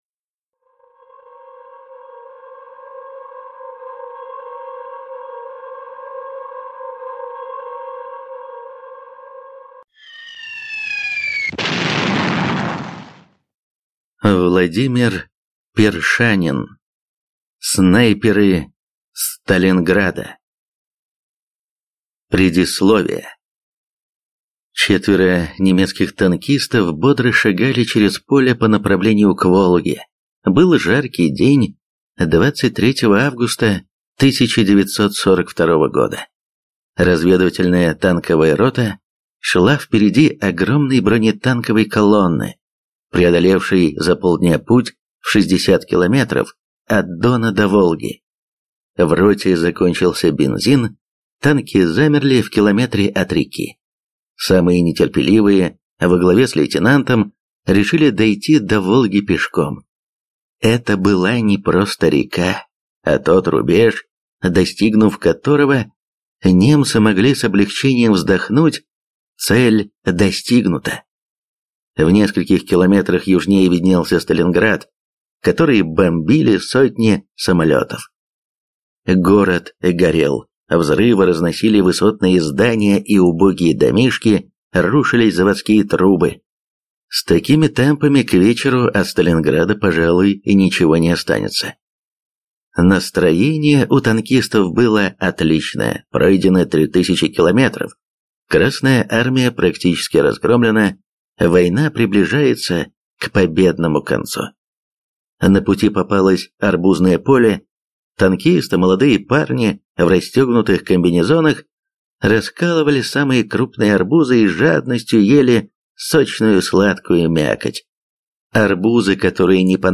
Аудиокнига Снайперы Сталинграда | Библиотека аудиокниг